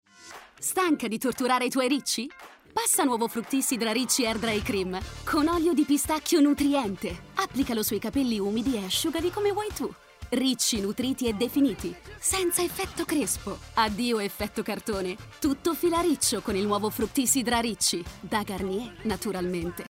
Commercial, Deep, Young, Natural, Distinctive
Commercial
Her voice is basically young, deep and calm, but also energetic, gritty, characterful.